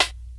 Snares
I Dont Know Snare.wav